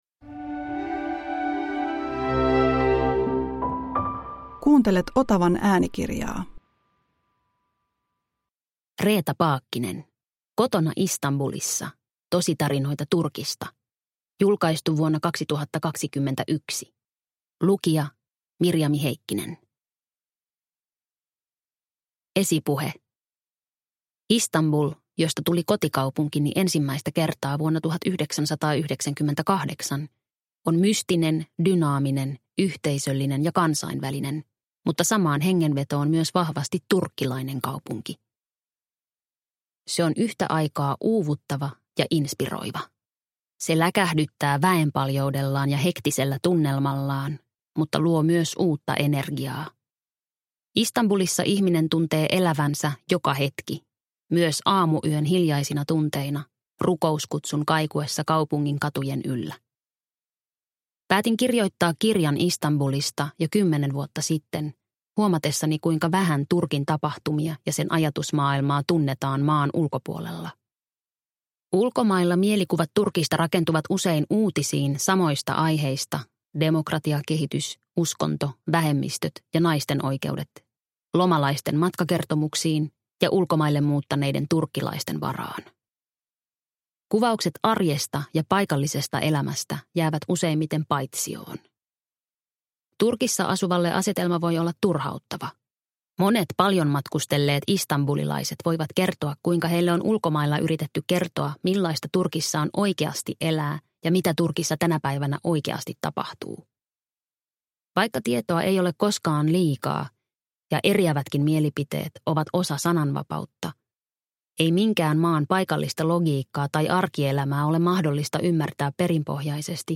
Kotona Istanbulissa – Ljudbok – Laddas ner